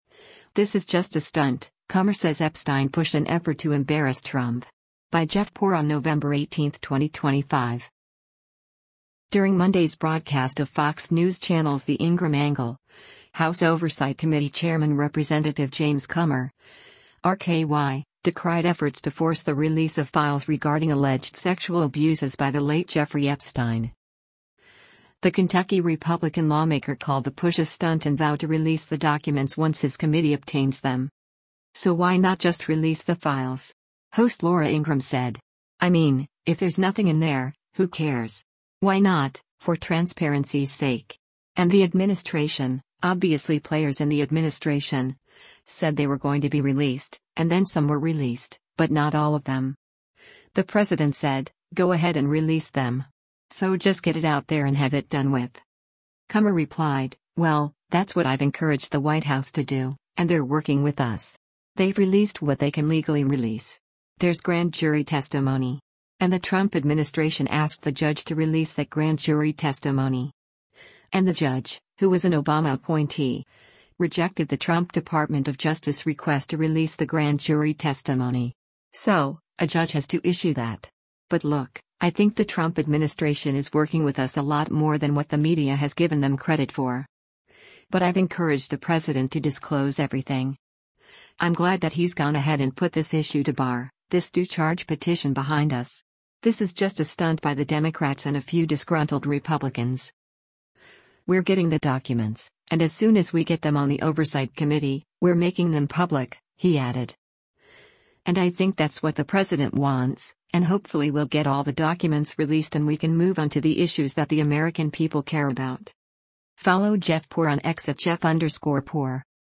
During Monday’s broadcast of Fox News Channel’s “The Ingraham Angle,” House Oversight Committee chairman Rep. James Comer (R-KY) decried efforts to force the release of files regarding alleged sexual abuses by the late Jeffrey Epstein.